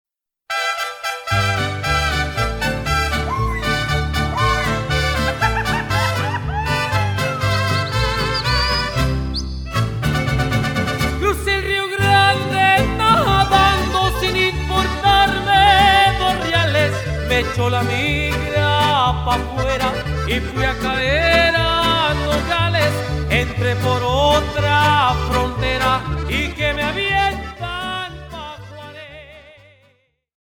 Mariachi music